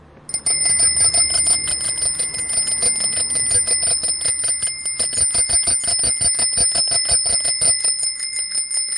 自行车铃
描述：自行车铃